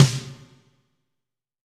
SNARE 030.wav